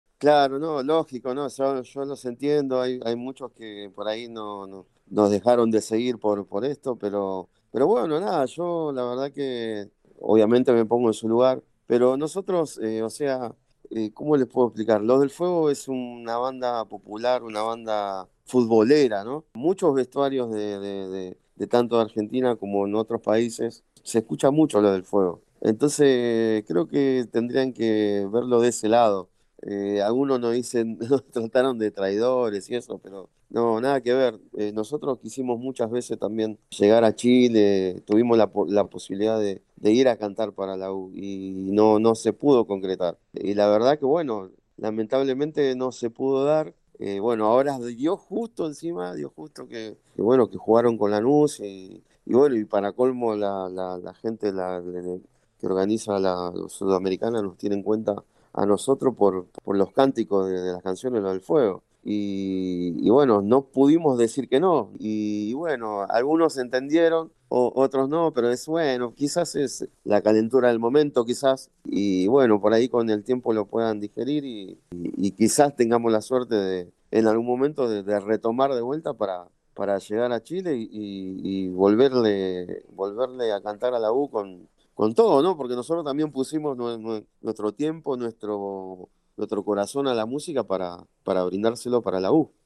en diálogo con ADN Deportes